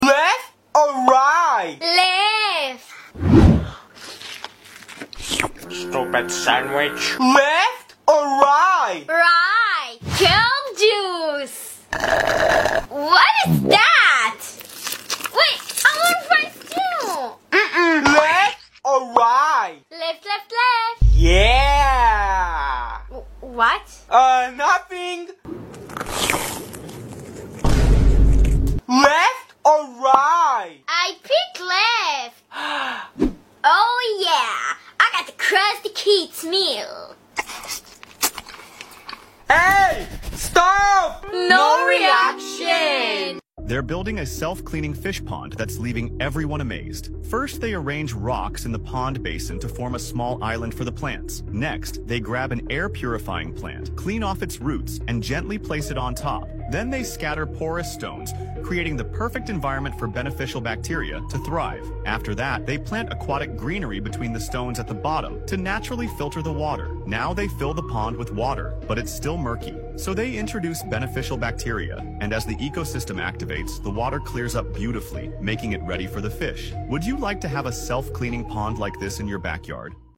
Spicy Indian Food ASMR!? 🇮🇳🥵 Sound Effects Free Download